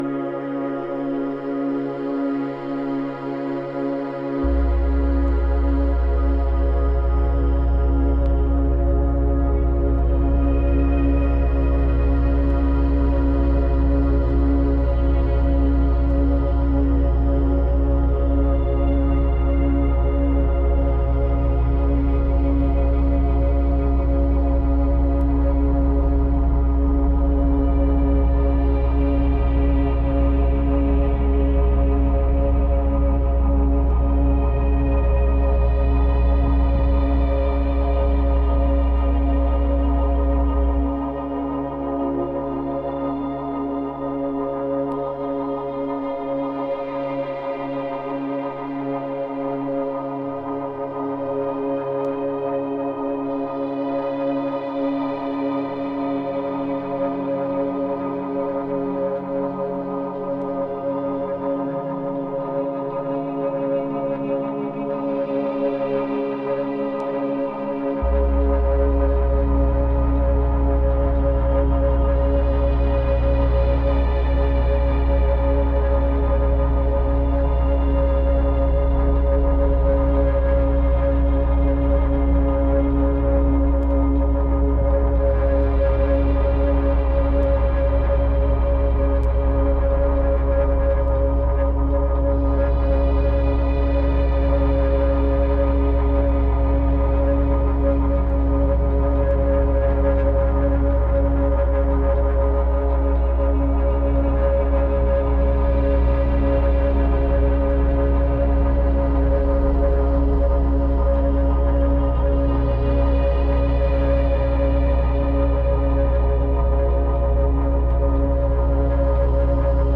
London techno producer
Lavishly colourful
Electronix Ambient